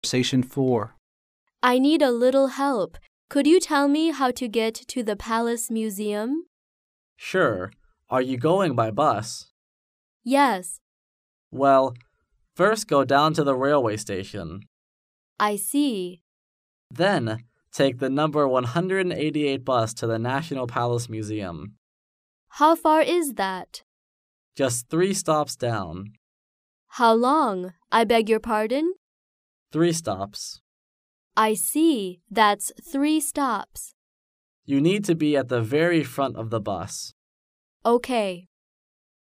Conversation 4